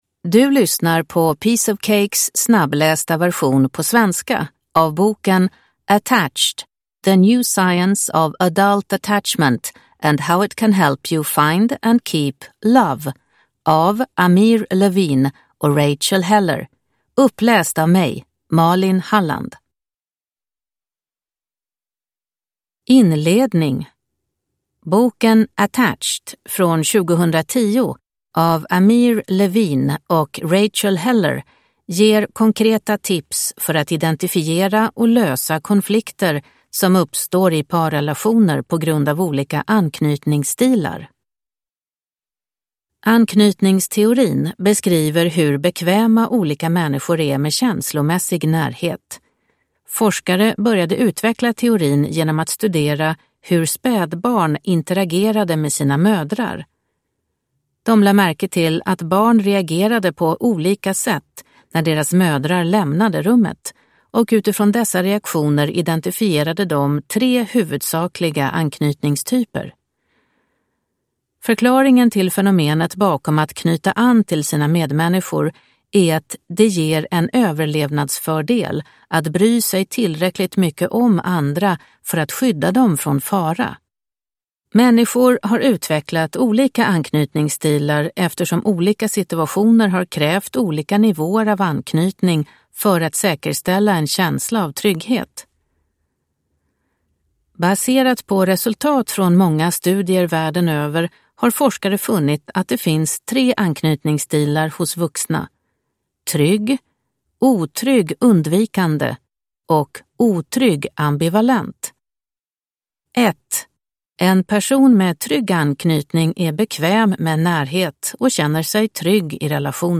Attached : En snabbläst version på svenska – Ljudbok